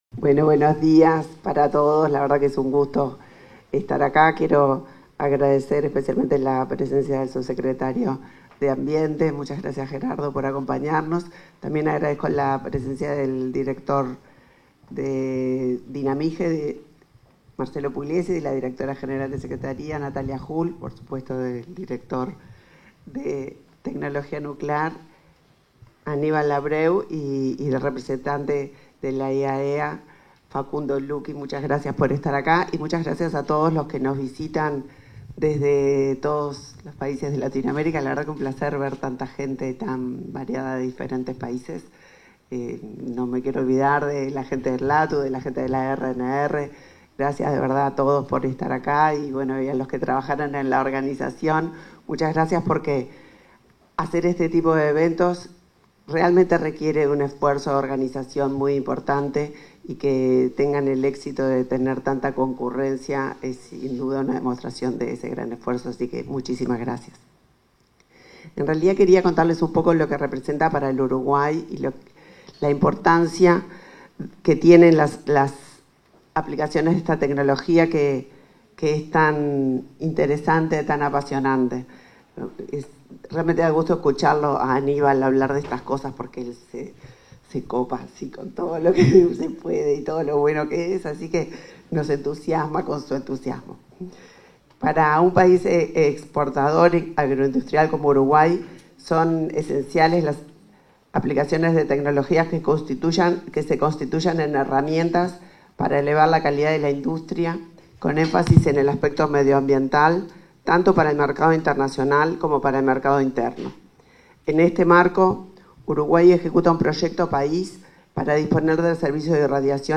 Palabras de la ministra de Industria, Energía y Minería, Elisa Facio
Palabras de la ministra de Industria, Energía y Minería, Elisa Facio 09/12/2024 Compartir Facebook X Copiar enlace WhatsApp LinkedIn En el marco del Taller Regional sobre el Diseño de Instalaciones de Irradiación para el Reciclado de Plástico y su Viabilidad Económica, este 9 de diciembre, se expresó la ministra de Industria, Energía y Minería, Elisa Facio.